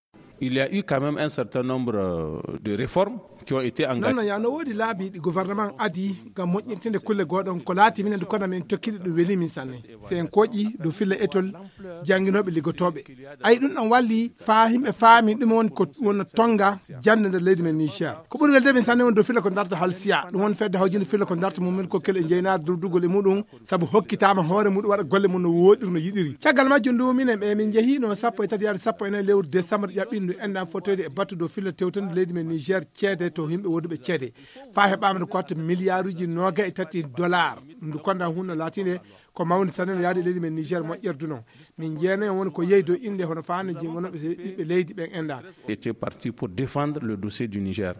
Magazine en fulfuldé